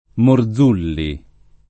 [ mor z2 lli ]